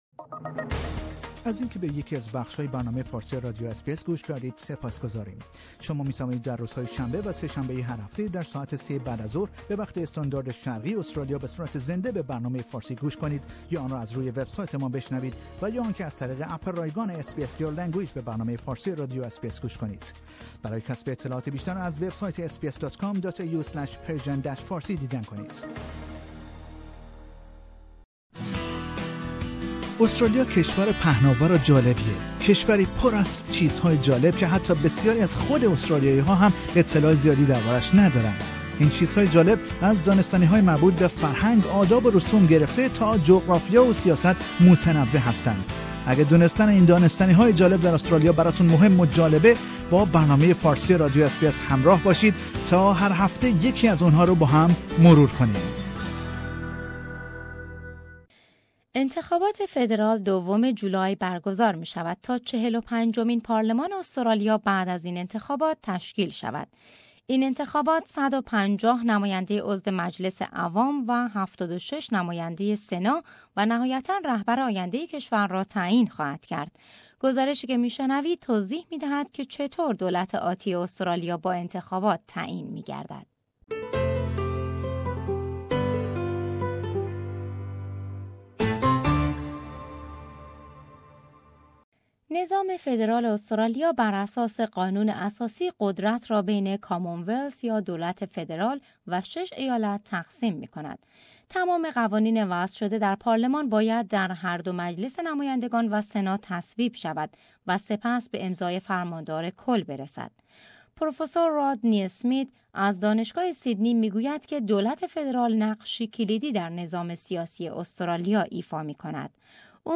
این انتخابات 150 نماینده عضو مجلس عوام و 76 نماینده سنا، و نهایتا رهبر آینده کشور را تعیین خواهد کرد. گزارشی که می شنوید، توضیح می دهد که چه طور دولت آتی استرالیا با انتخابات تعیین می گردد.